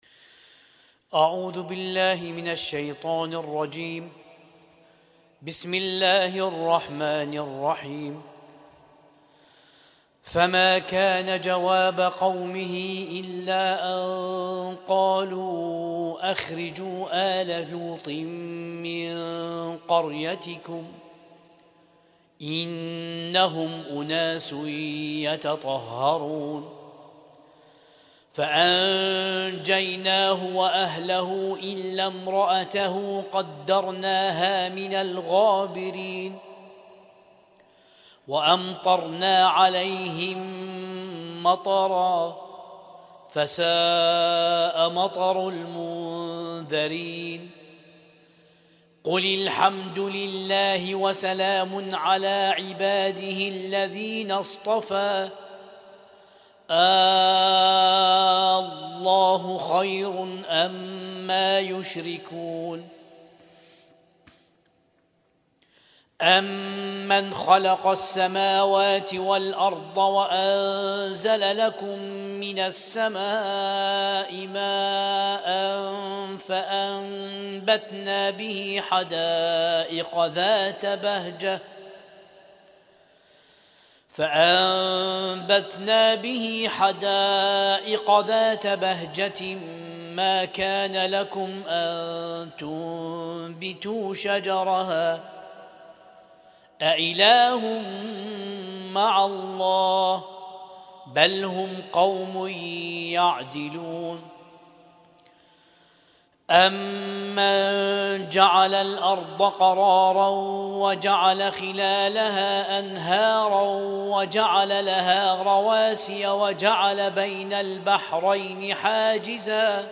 القارئ: القارئ